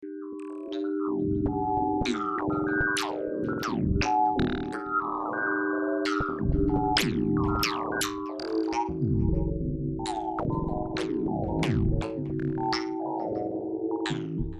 哥斯达黎加的户外活动 " 夜莺之声
描述：傍晚时分，在哥斯达黎加阿雷纳尔火山的山脚下，普通的保拉克在互相交谈
标签： 夜鹰 昆虫 性质 阿雷纳 鸟类 虫子
声道立体声